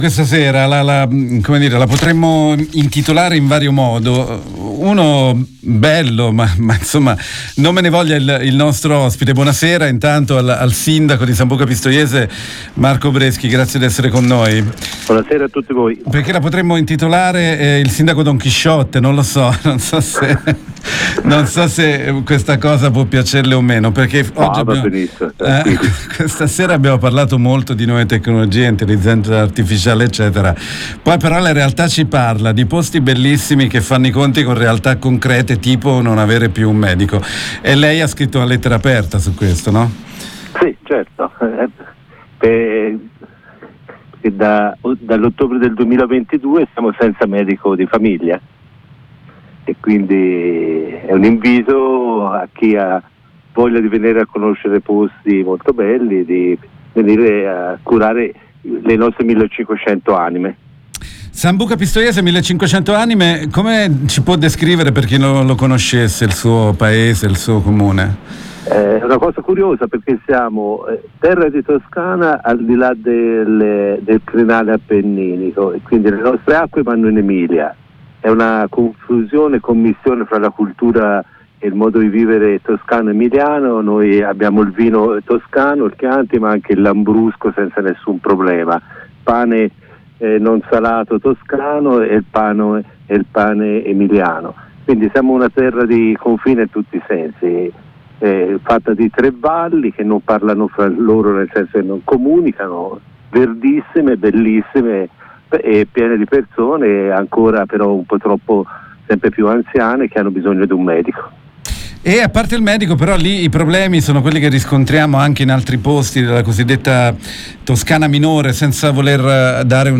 la giunta di Sambuca PistoieseIl sindaco Marco Breschi ha scritto una lettera aperta. lo abbiamo intervistato